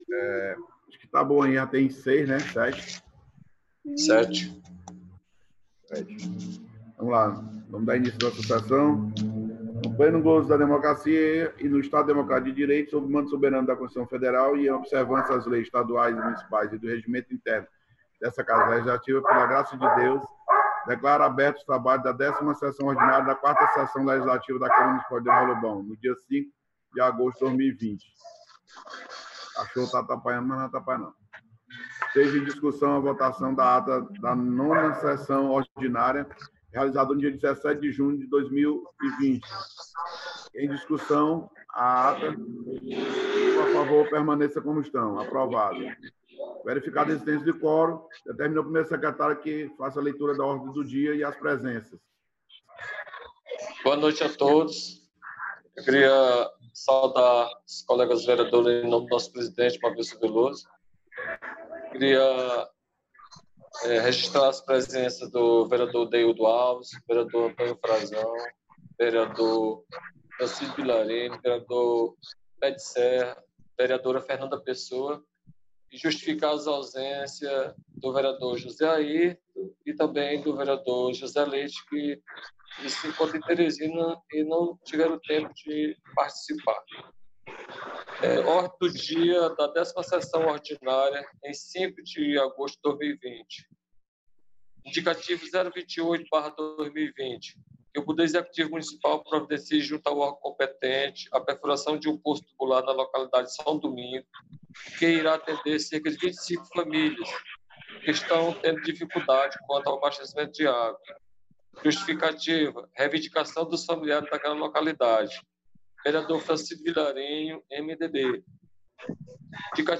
10ª Sessão Ordinária 05 de Agosto